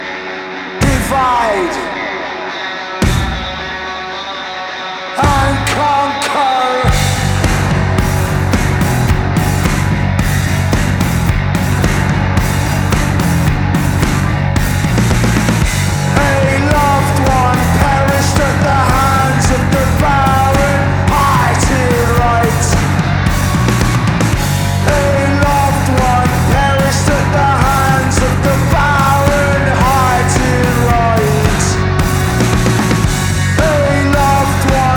2017-03-09 Жанр: Рок Длительность